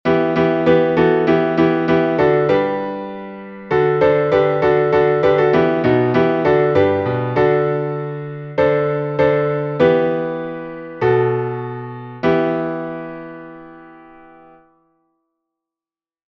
Напев Киево-Печерской Лавры, глас 6